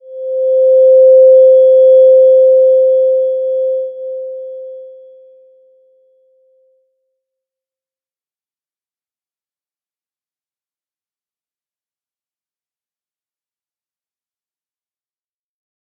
Slow-Distant-Chime-C5-p.wav